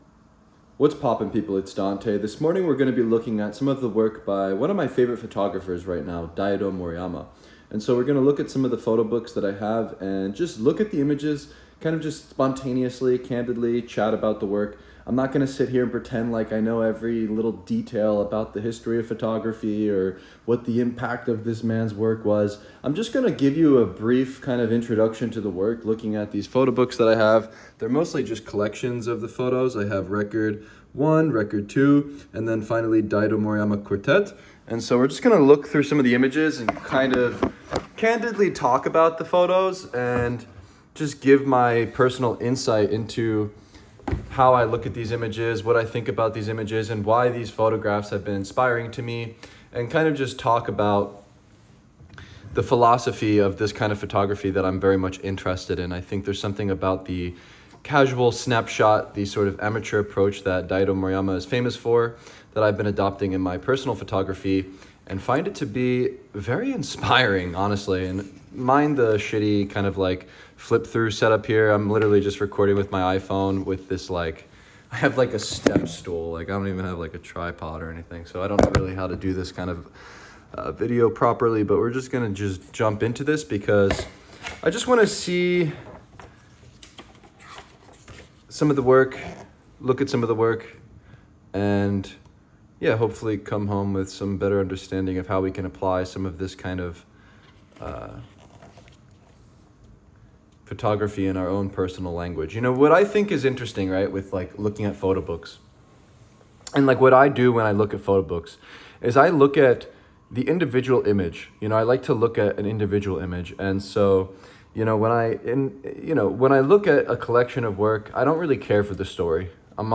Low-tech setup warning: it’s literally my iPhone and a step stool.